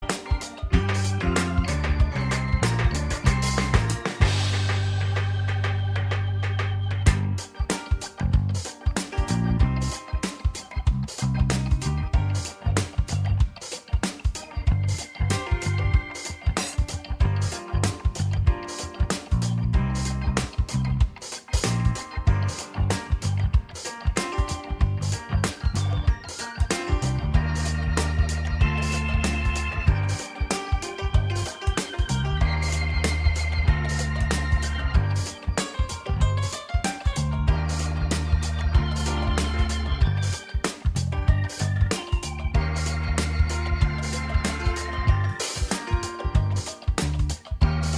Minus All Guitars Soft Rock 4:36 Buy £1.50